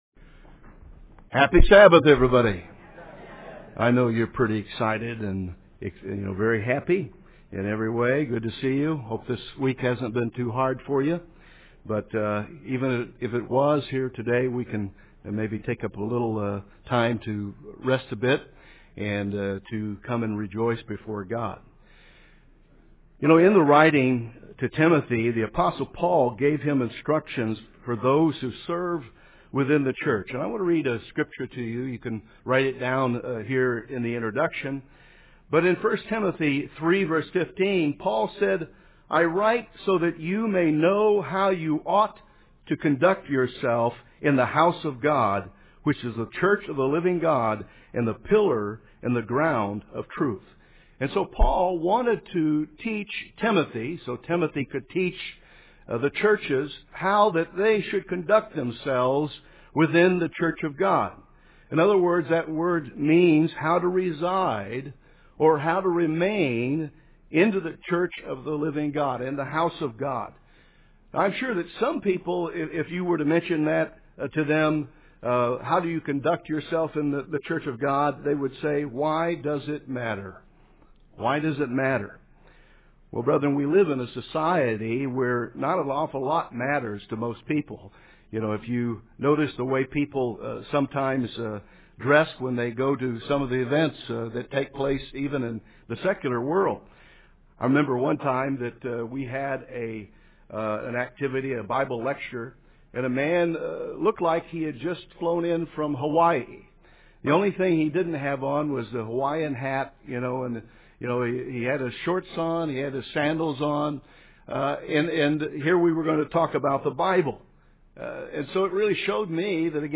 Presumptuousness, pride and arrogance vs Humility UCG Sermon Transcript This transcript was generated by AI and may contain errors.